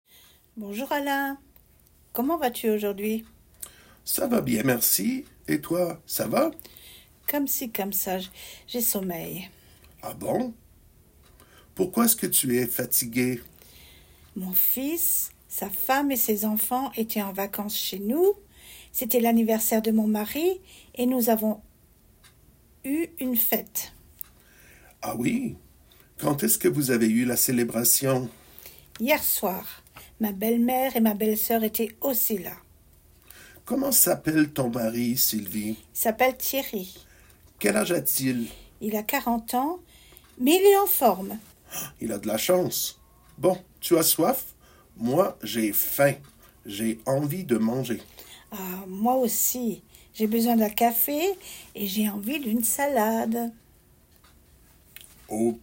Dialogue – Un 3 | FrenchGrammarStudio